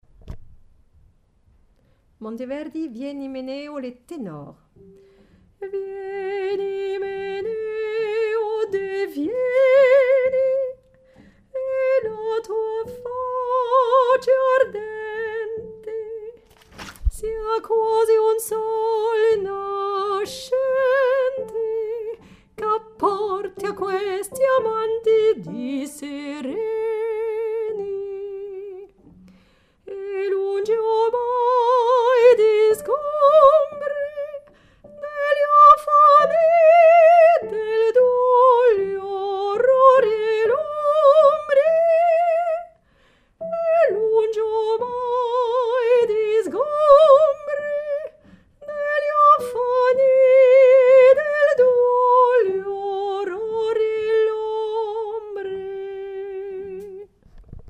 Ténor
vieni_Tenor.mp3